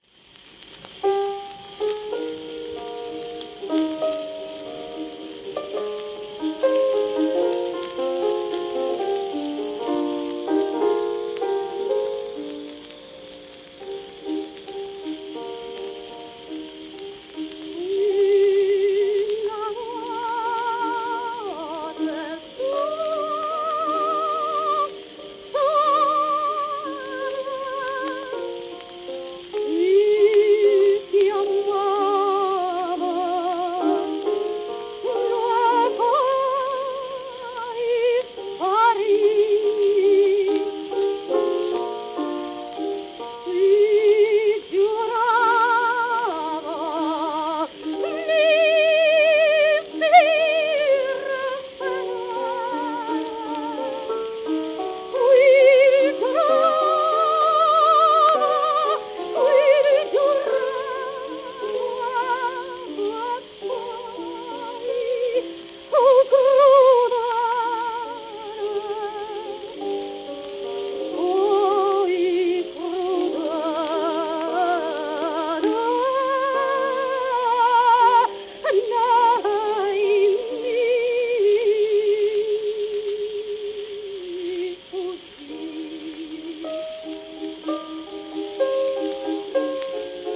w/piano
旧 旧吹込みの略、電気録音以前の機械式録音盤（ラッパ吹込み）